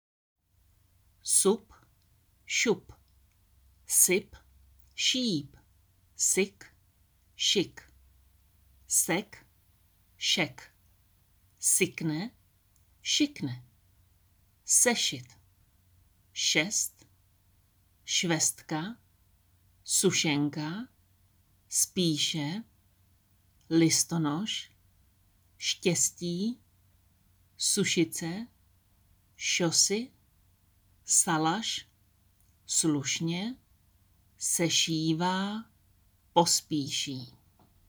Tady si můžete stáhnout audio na výslovnost S a Š.